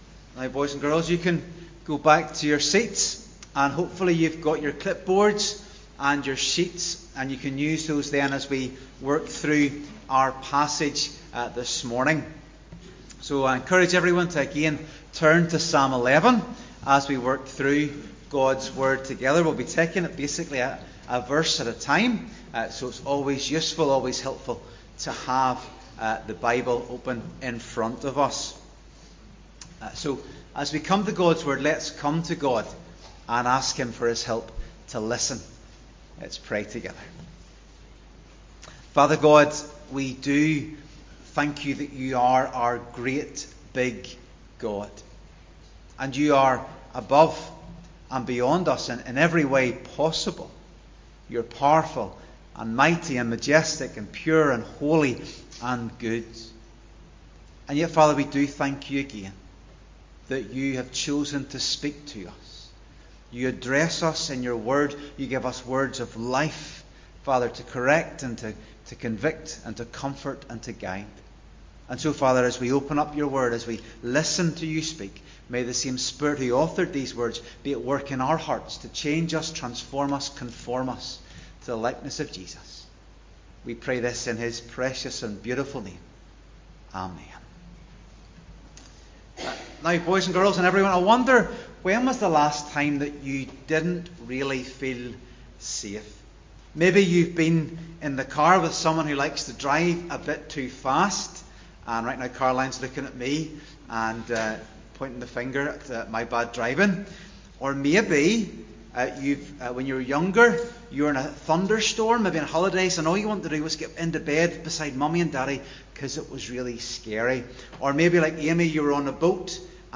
Psalm 11:1-7 Service Type: Sunday Morning Worship Psalm 11 The God Who is a Refuge Introduction When was the last time you didn’t feel safe?